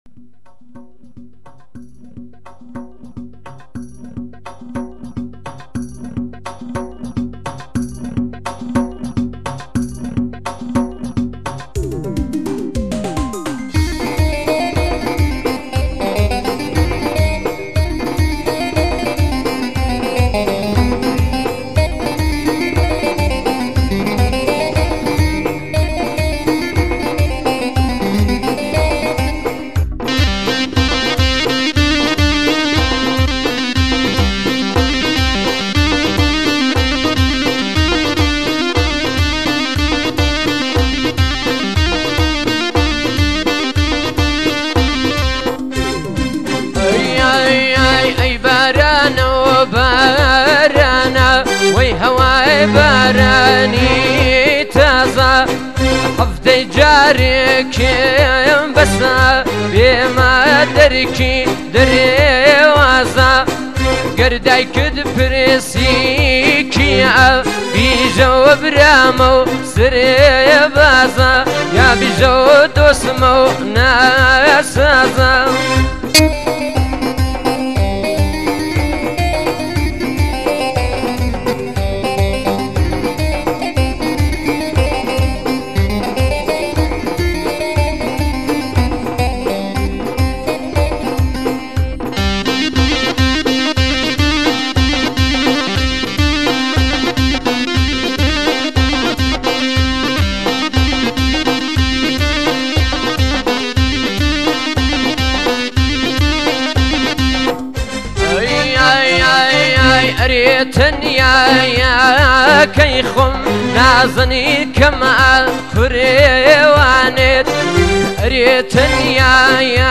آهنگ بسیار زیبایی کردی